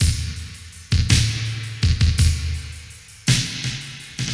• 110 Bpm 80's Breakbeat Sample D# Key.wav
Free breakbeat - kick tuned to the D# note. Loudest frequency: 3377Hz
110-bpm-80s-breakbeat-sample-d-sharp-key-NxG.wav